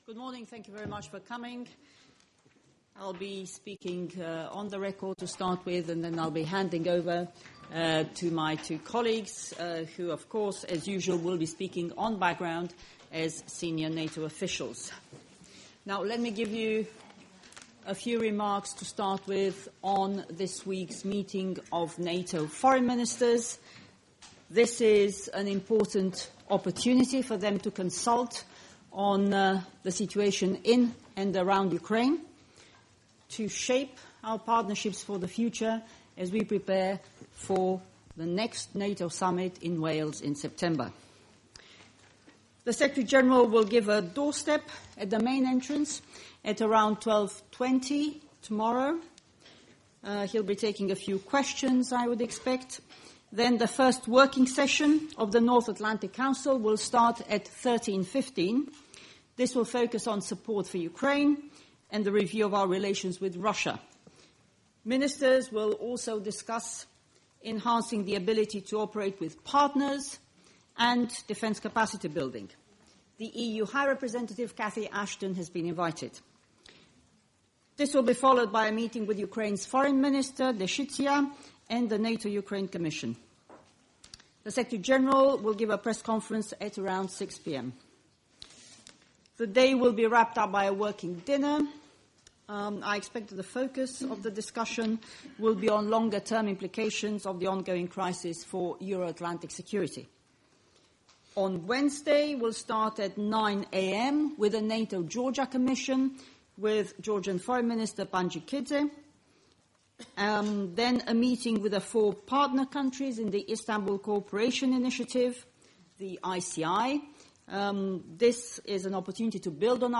at the pre-ministerial briefing